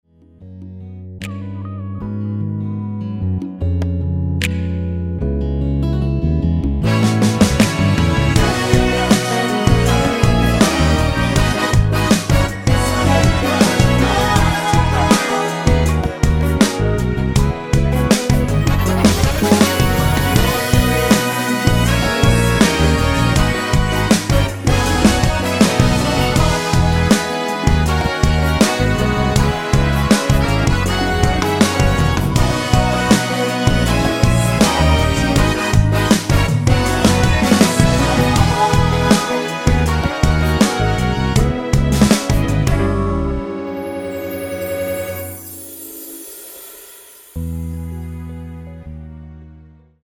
처음 시작 보컬 부분은 코러스가 아니기 때문에 직접 노래를 하셔야 됩니다.
원키에서(-2)내린 코러스 포함된 MR 입니다.(미리듣기 참조)
앞부분30초, 뒷부분30초씩 편집해서 올려 드리고 있습니다.
중간에 음이 끈어지고 다시 나오는 이유는